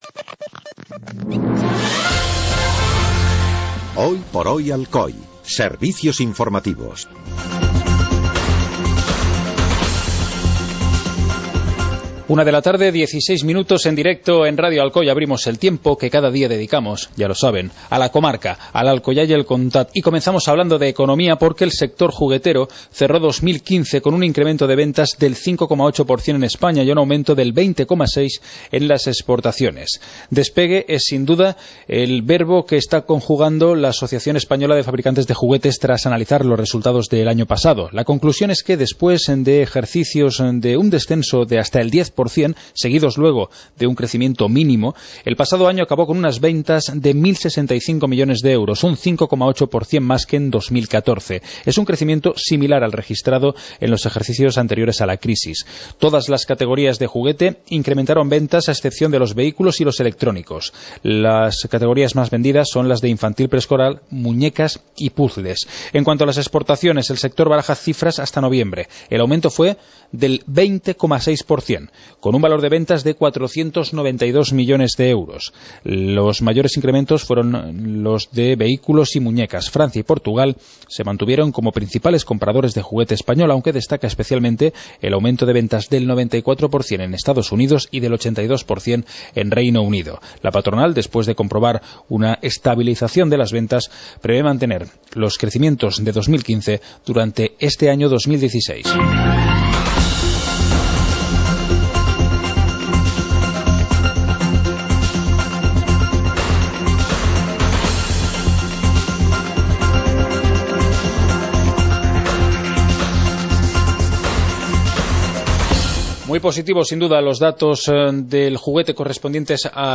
Informativo comarcal - jueves, 04 de febrero de 2016